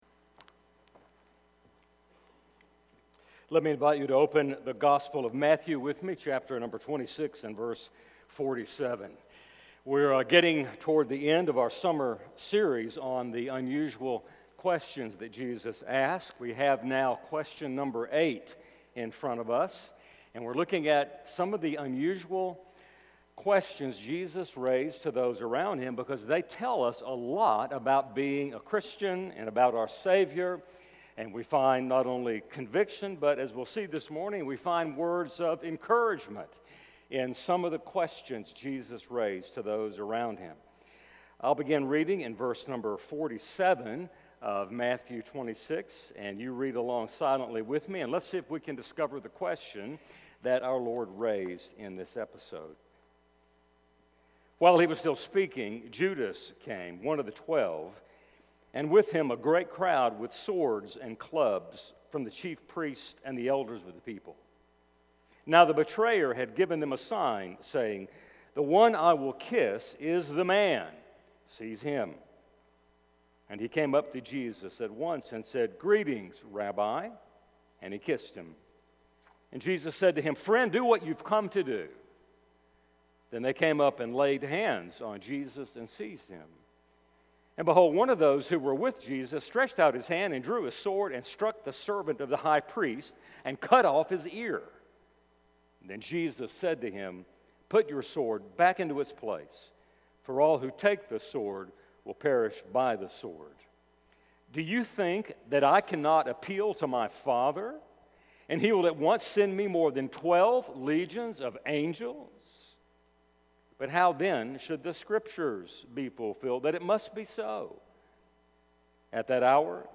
Matthew 26:53 Service Type: Sunday Morning Each week as we gather for worship before Our Lord